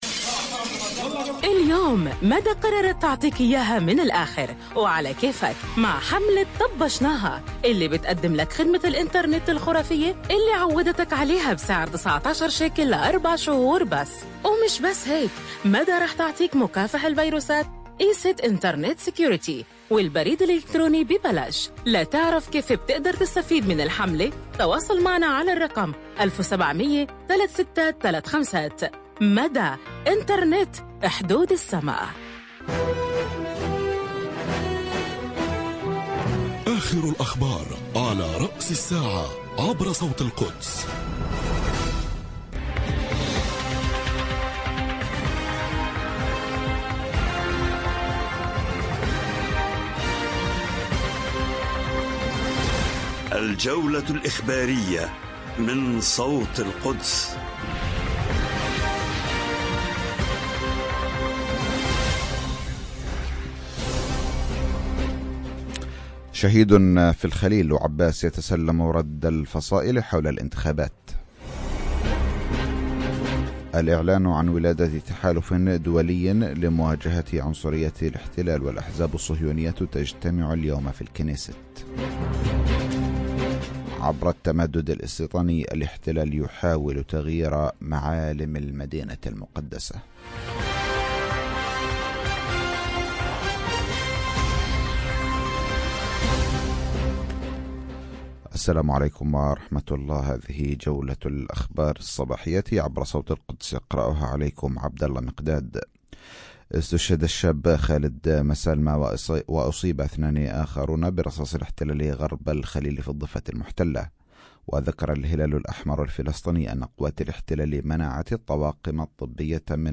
نشرات الأخبار